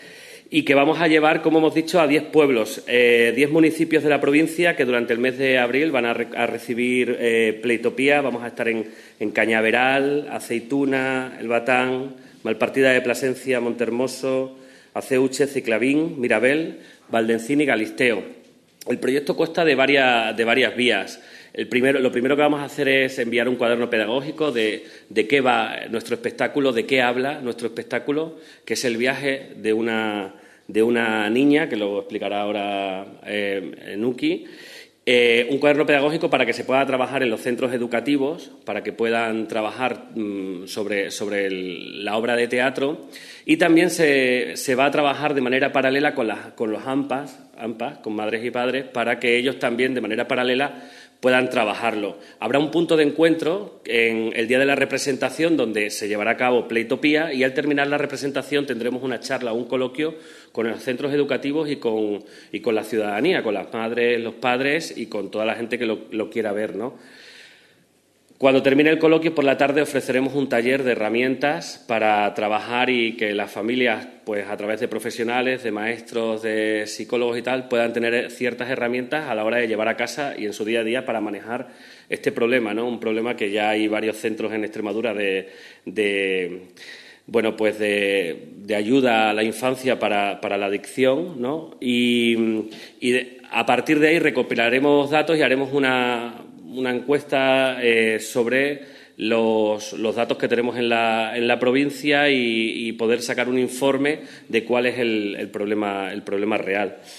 han presentado en rueda de prensa el proyecto Playtopía o el derecho jugar”. Se trata de una iniciativa que combina teatro, música, humor y audiovisuales para abordar la dependencia tecnológica en la infancia y la necesidad de recuperar el juego como espacio de crecimiento y libertad.